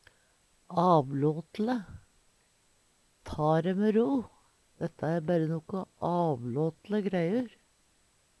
avlåtele - Numedalsmål (en-US)
Tilleggsopplysningar gjera seg til for bagateller Sjå òg åfLøymin (Veggli) Høyr på uttala